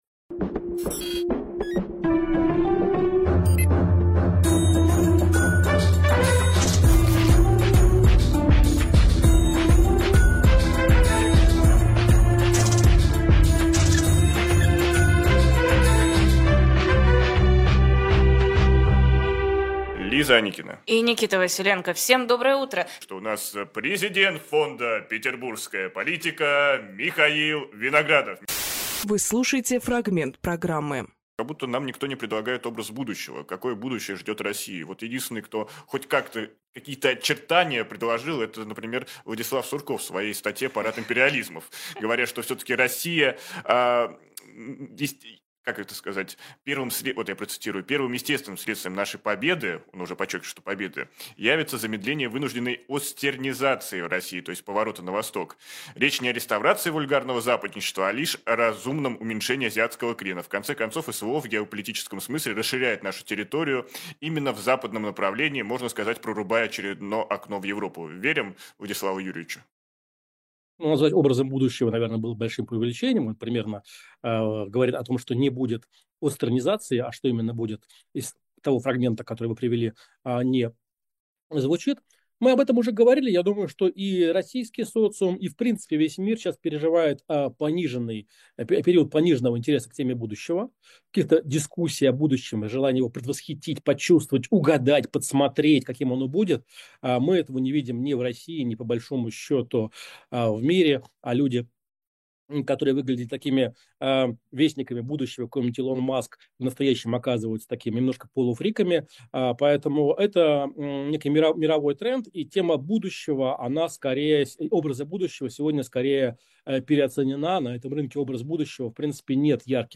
Фрагмент эфира от 05.01.25